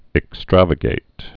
(ĭk-străvə-gāt)